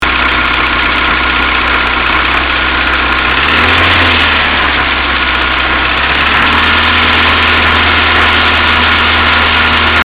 Звук двигателя
Вначале в холостую, потом газую.
странно по общему звуку судить грохотать может чо угодно от клапанов до подшипников генератора или ролика или вала в кпп.
вобщемто как и предпологалось криминала неслышно,но это незначит что его нет.походу один цилиндр косячит,это не на слух а по графику муз редактора.